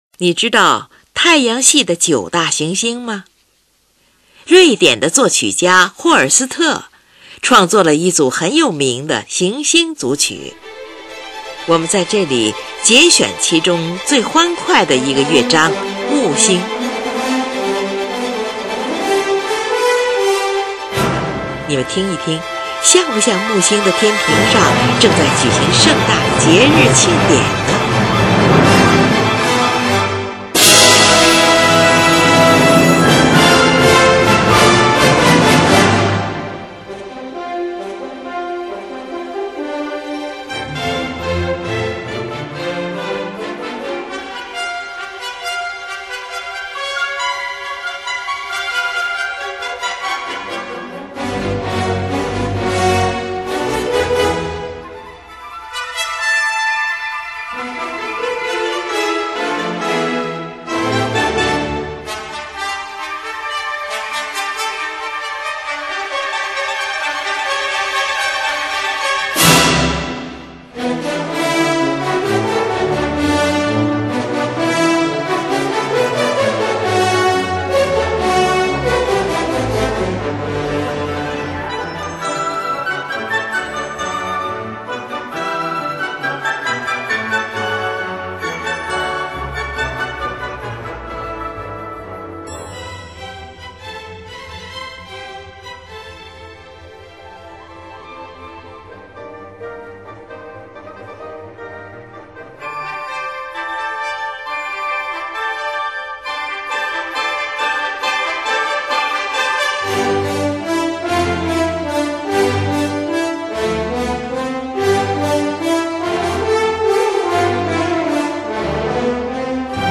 用速度标记表示出来的是：快速的-行进式的-快速的。
中段十分庄严而高贵，带有着一种近乎严肃的忧郁感。主题由一首虔诚的颂歌构成，充满东方五声调式的韵味。
第二主题是一个歌唱性的旋律，有六支法国号及弦乐器组奏出；
整个乐章在民间节庆般的高潮后，以一个雄伟的短小尾声作为结束。